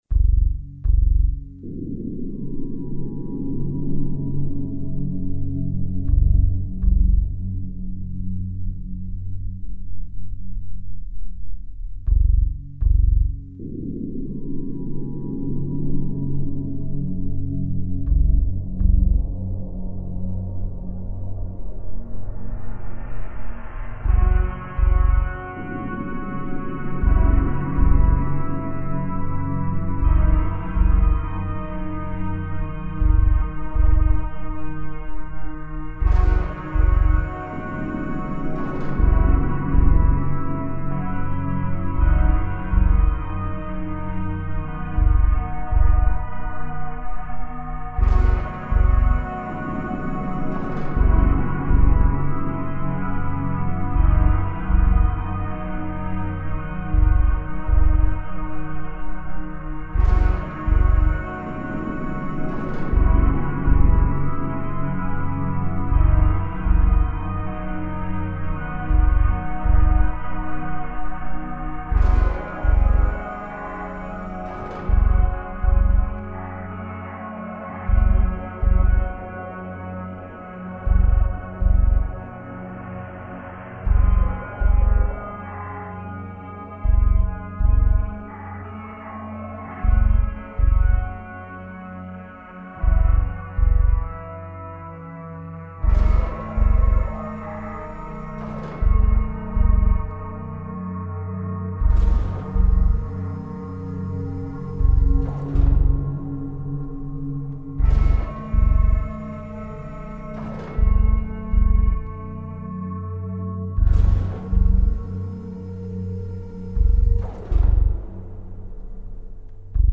Gothic ambient.